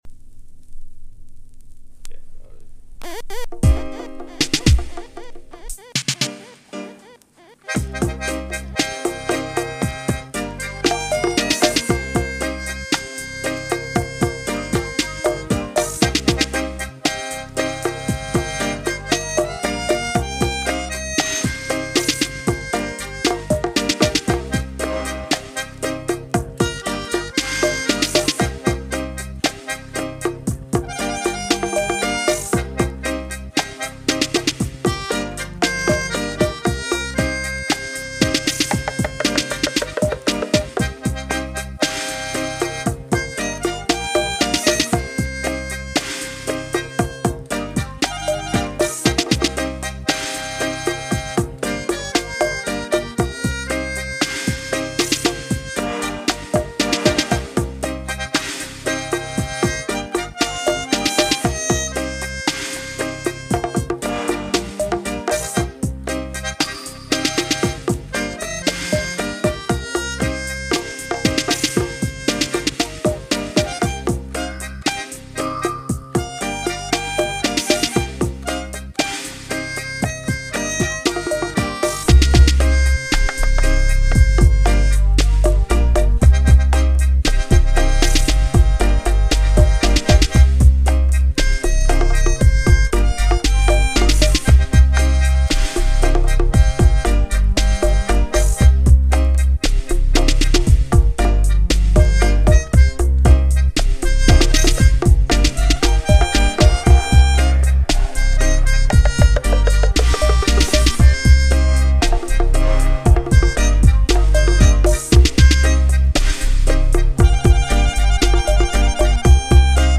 Strictly Vinyl